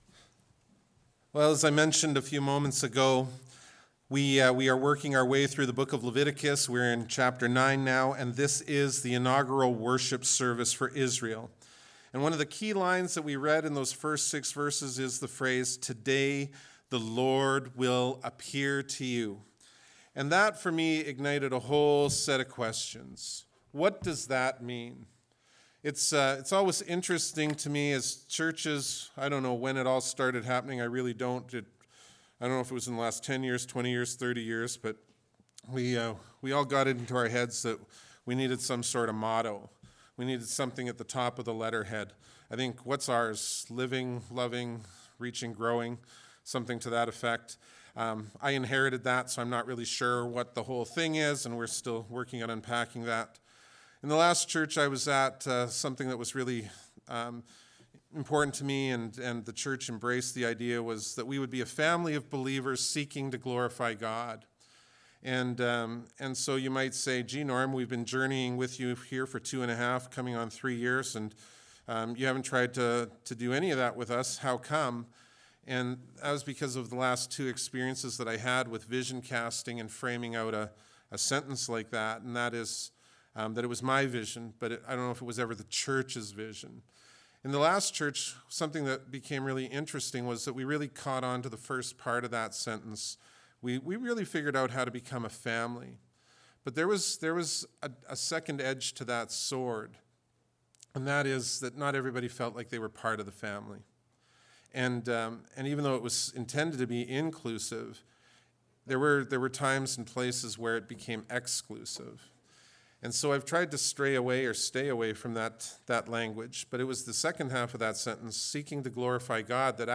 Bible Text: Leviticus 9:1-6 | Preacher